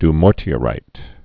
(d-môrtē-ə-rīt, dy-)